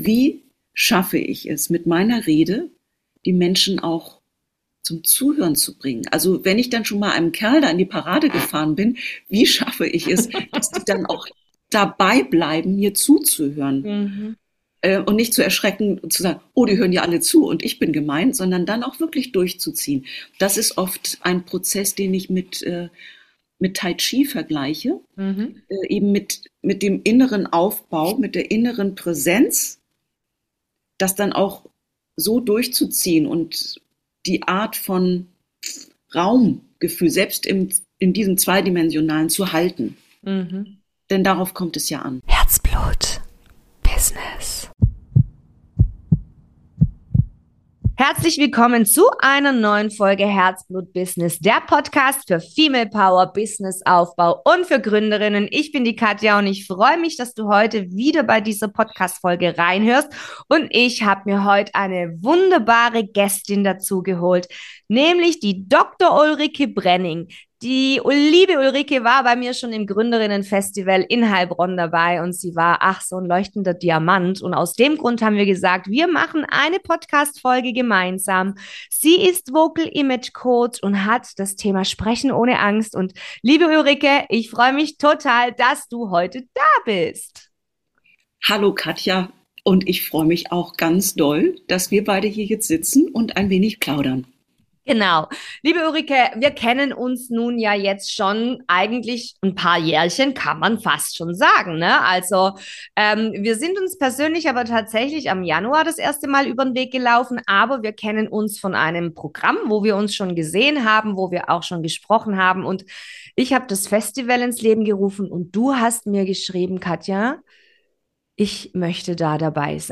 #93 Interview